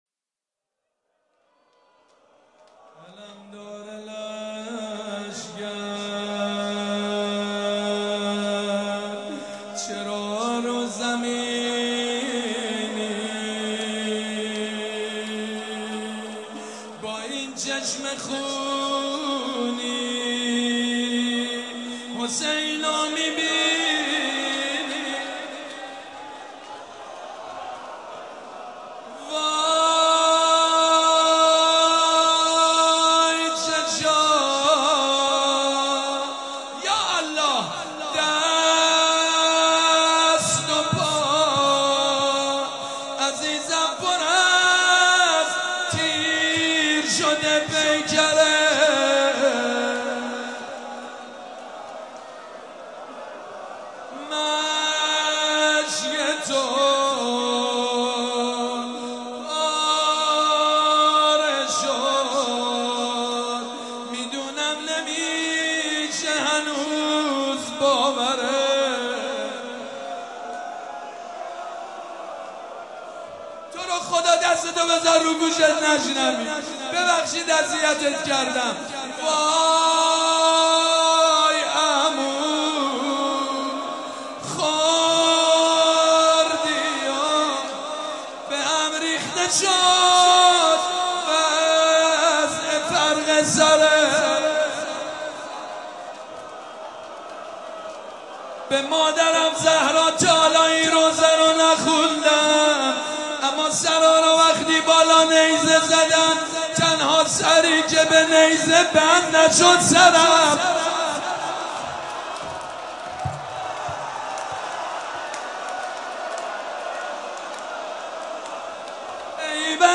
نوحه جديد بنی فاطمه